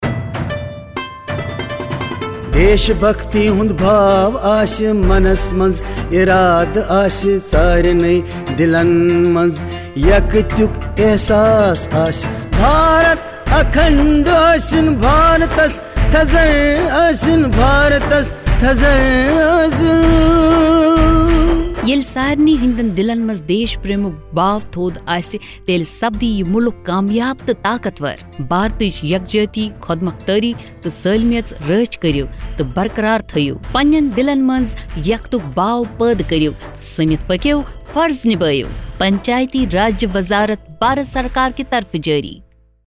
82 Fundamental Duty 3rd Fundamental Duty Protect sovereignty & integrity of India Radio Jingle Kashmiri